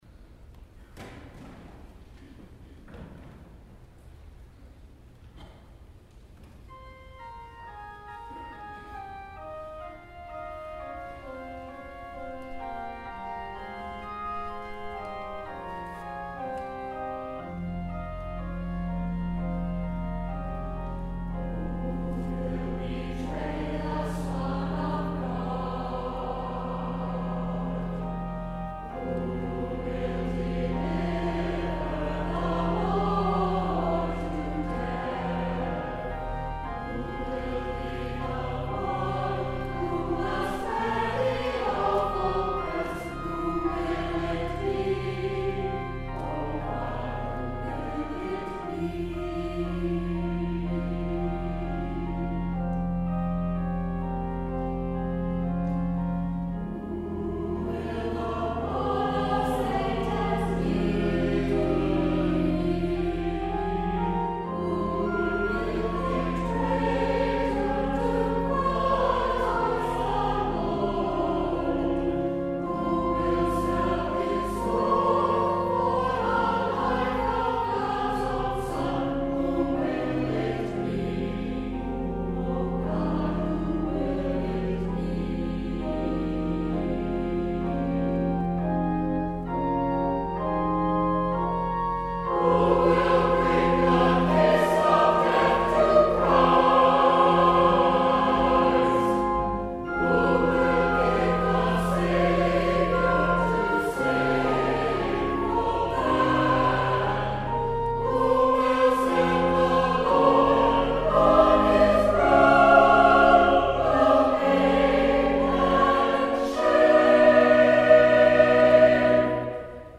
The St. William choir presented a Tenebrae Service on Palm Sunday 2015.